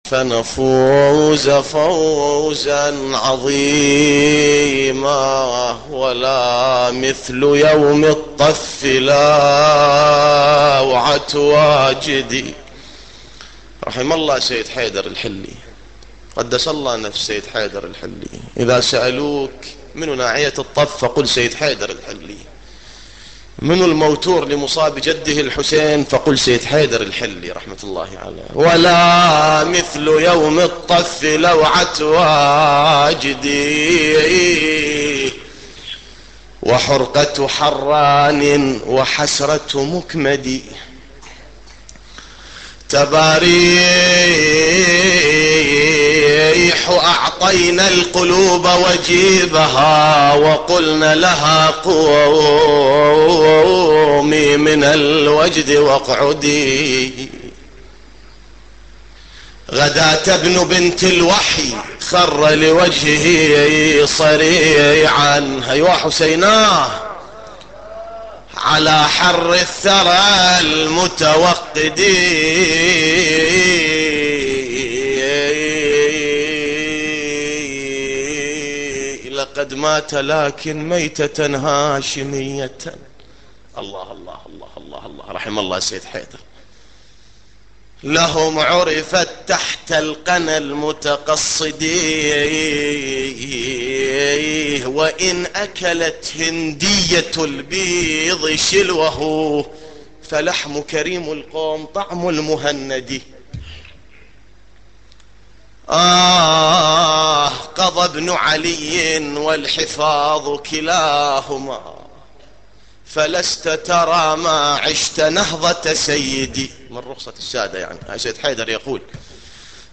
نواعي حسينية 14